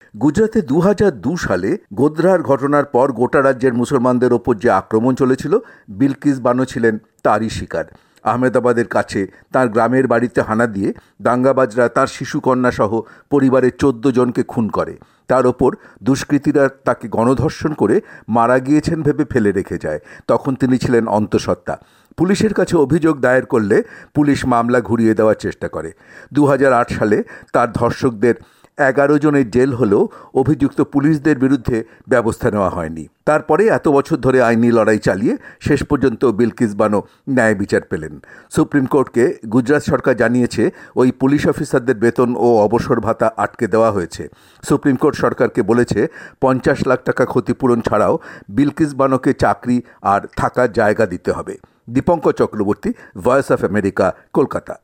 প্রতিবেদন।